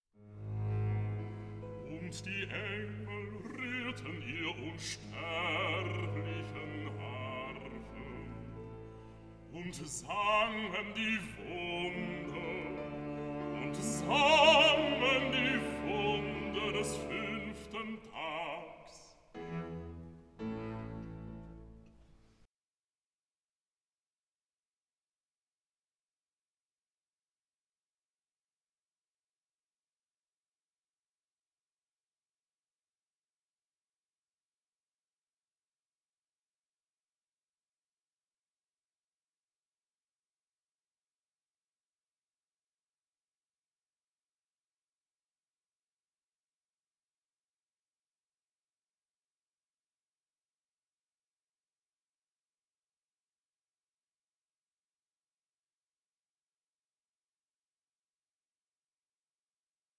Classical transparency and Romantic drive